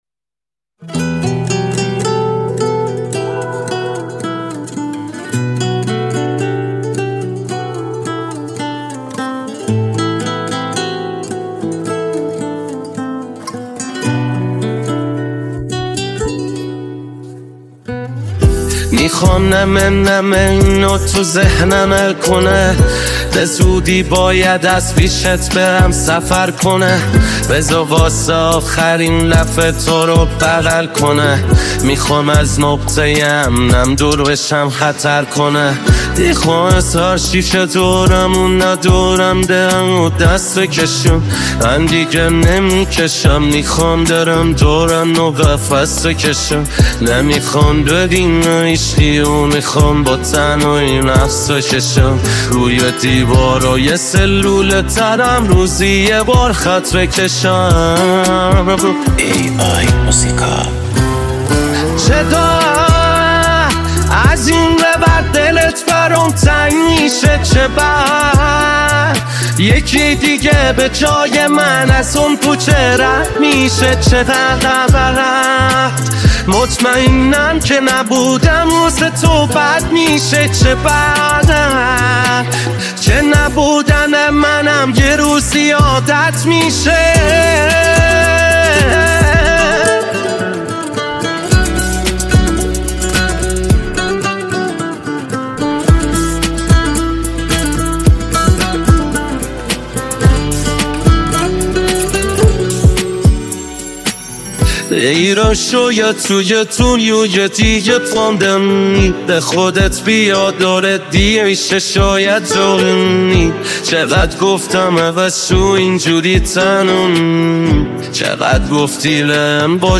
download new ai generated music